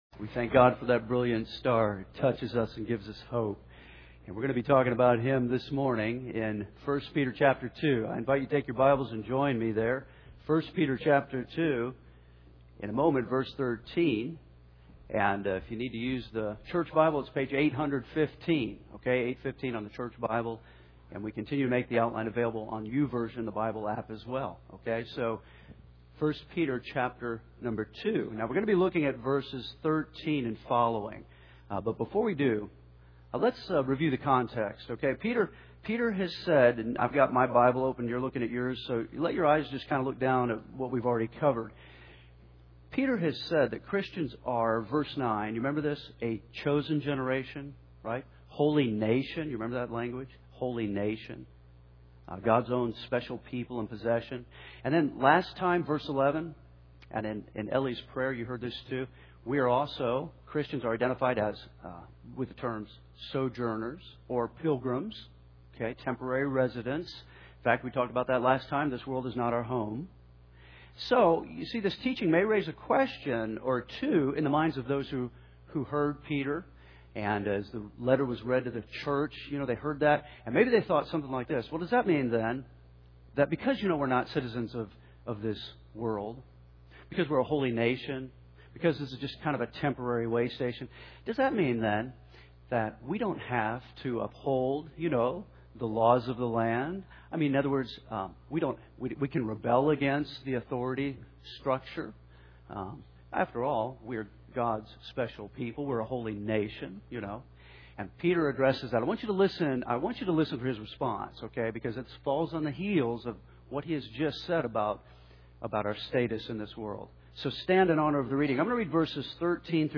Henderson’s First Baptist Church, Henderson